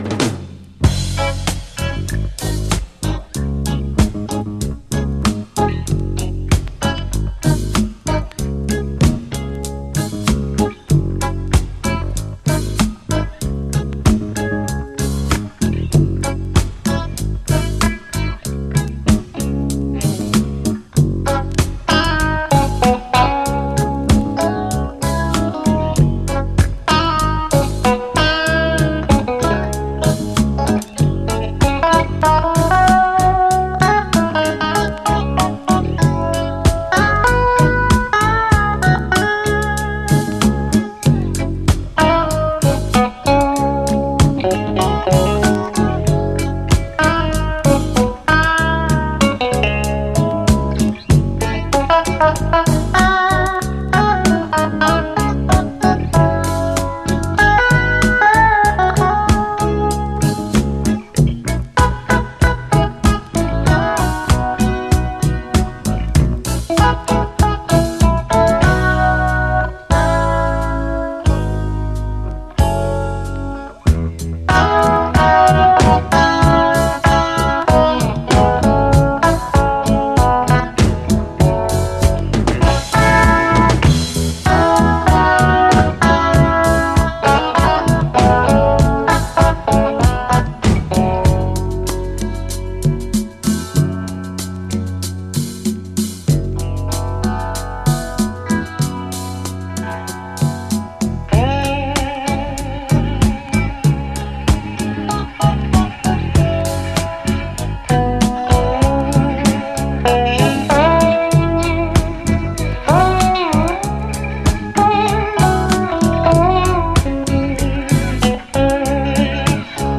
SSW / AOR, REGGAE, ROCK, 7INCH
ギターがメインのメロウで浮遊感あるレゲエ・チューンなんですが